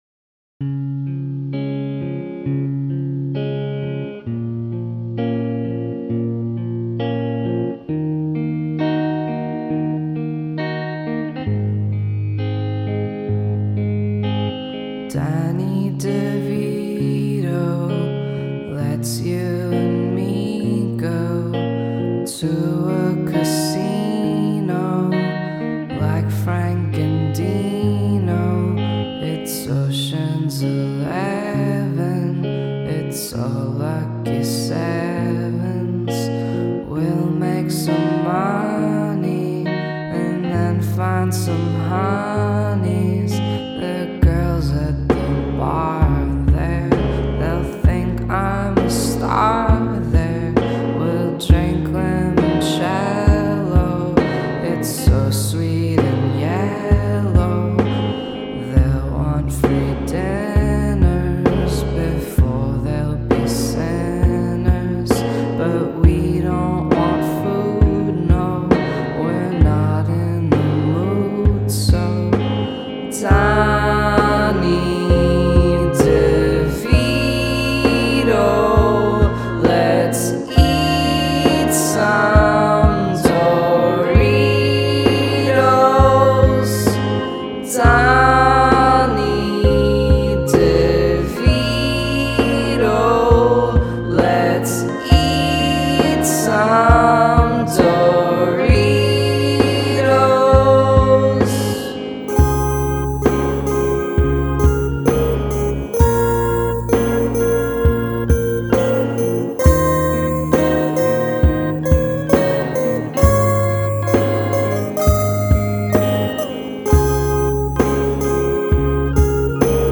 the spacey bass/kick in the verses, the bitcrushed lead guitar
i sing in a weird accent
the chorus melody is at the top of my range and i am struggling to hang there and there is no autotune.